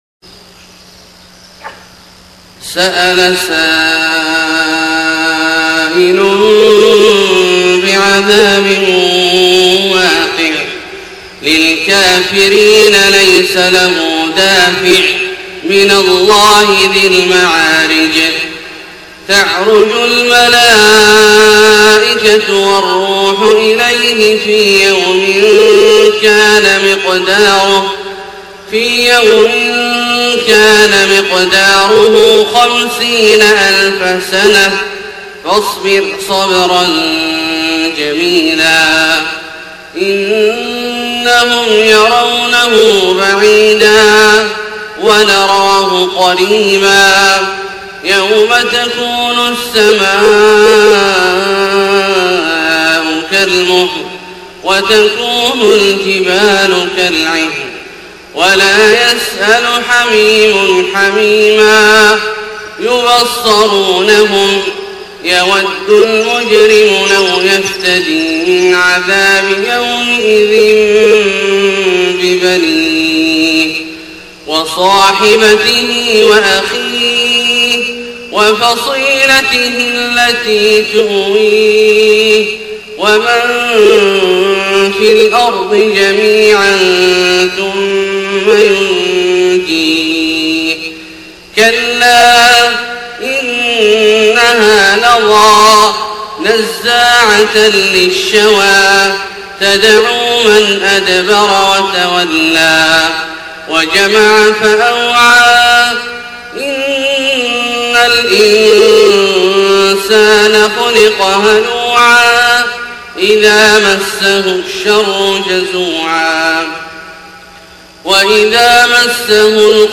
صلاة الفجر 8 ربيع الأول 1431هـ سورة المعارج كاملة > 1431 🕋 > الفروض - تلاوات الحرمين